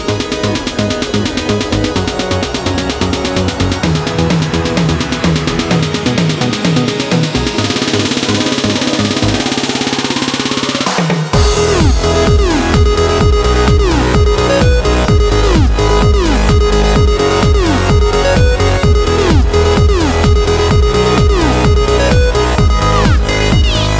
no Backing Vocals Dance 3:30 Buy £1.50